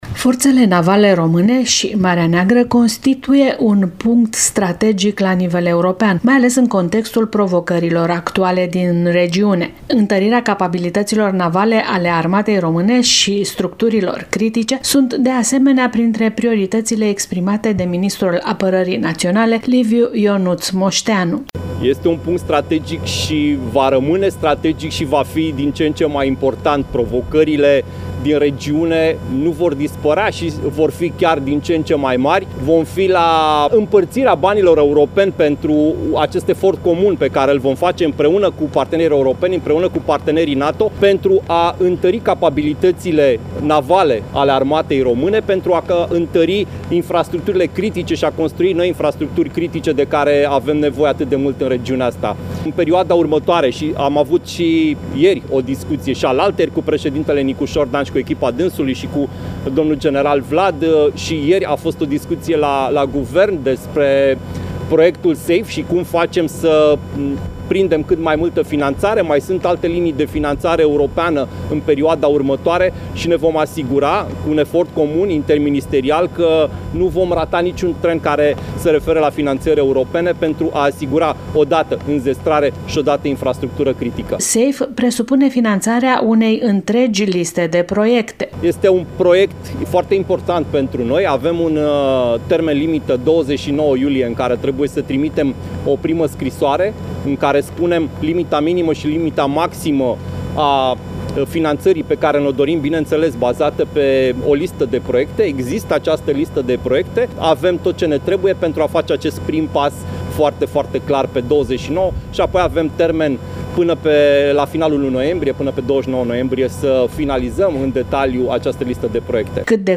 Prezent la Constanța, la ceremonia de preluare de către România a comenzii Grupului operativ de combatere a minelor marine în Marea Neagră, noul Ministrul Apărării Naționale, Liviu Ionuț Moșteanu a reliefat importanța Forțelor Navale Române, ca punct strategic la nivel european, mai ales în contectul provocărilor actuale din regiune.